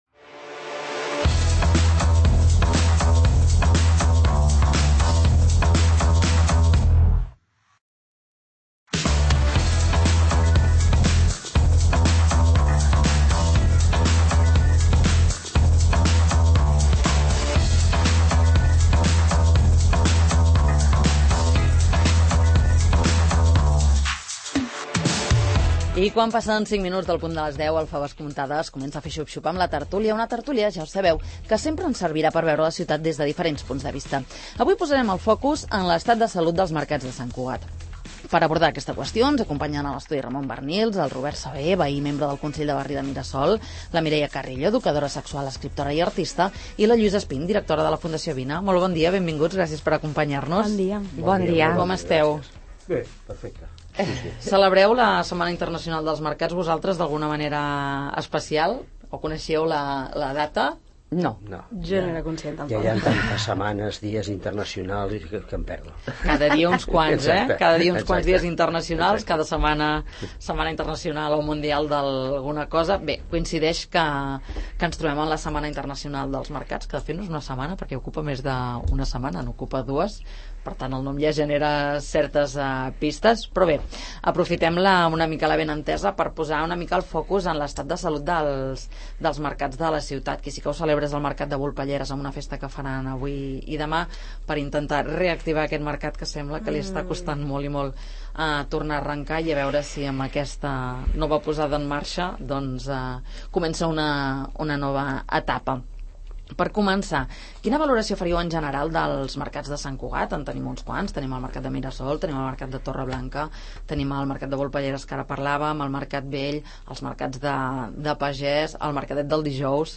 Quin seria el millor model de mercat a Sant Cugat? A debat a la tert�lia del 'Faves comptades'